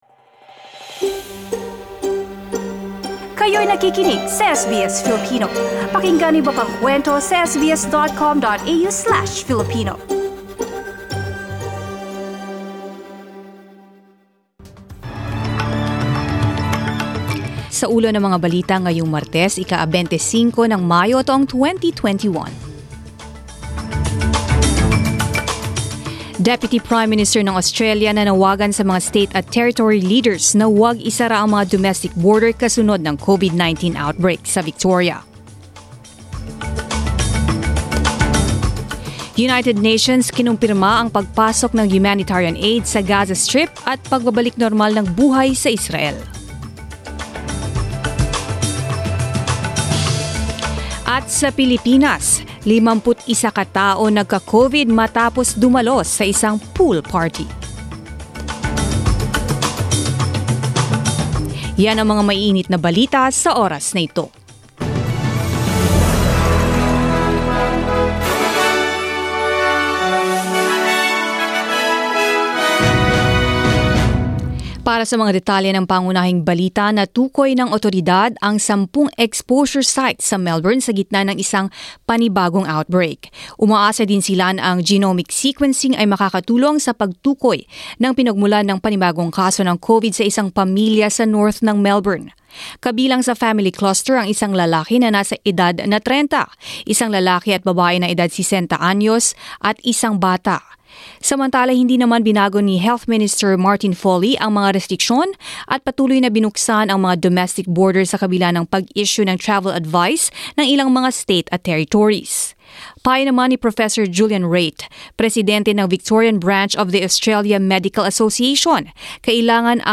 Mga balita ngayong ika-25 ng Mayo